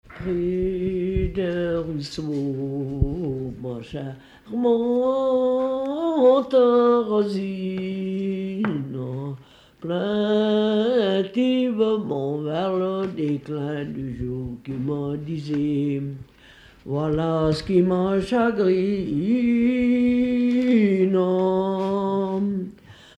Mieussy
Pièce musicale inédite